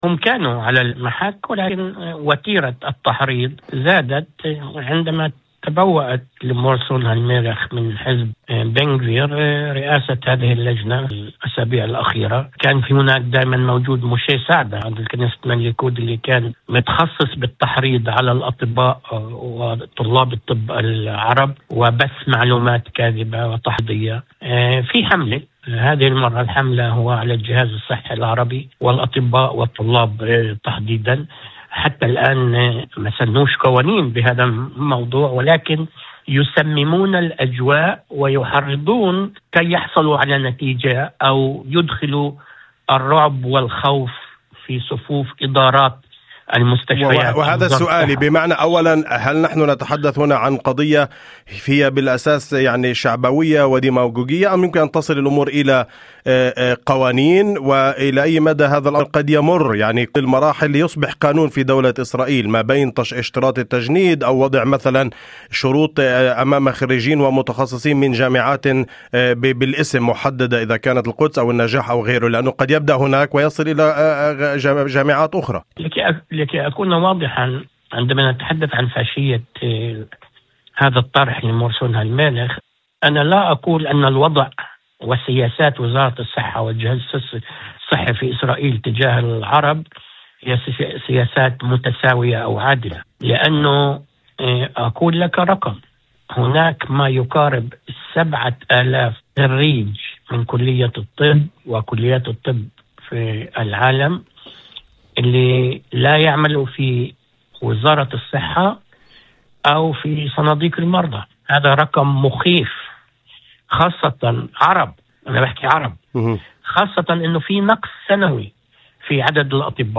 وأضاف في مداخلة هاتفية لبرنامج "أول خبر"، على إذاعة الشمس، أن ما يجري هو استهداف مباشر للجهاز الصحي العربي، محذرا من أن هذا التحريض قد يترجم عمليا إلى عرقلة قبول طلاب للتدريب أو التخصص، حتى دون قرارات رسمية، نتيجة خلق مناخ ترهيب غير معلن.